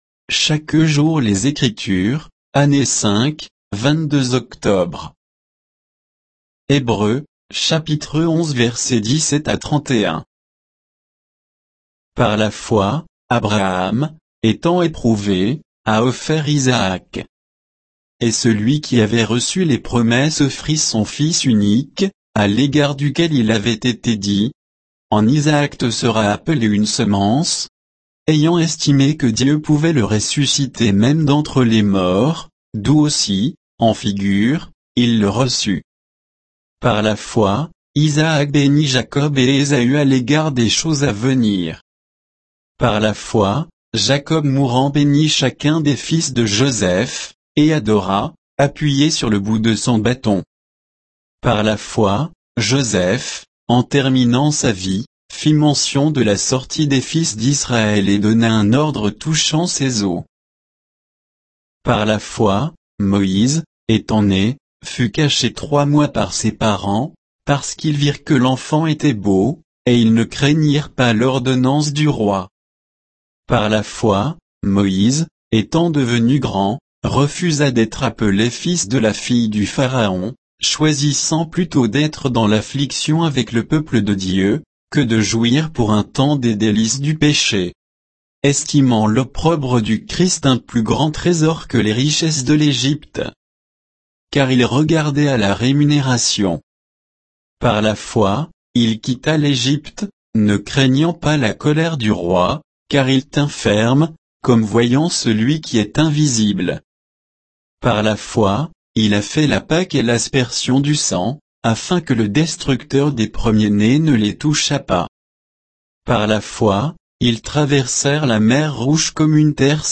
Méditation quoditienne de Chaque jour les Écritures sur Hébreux 11, 17 à 31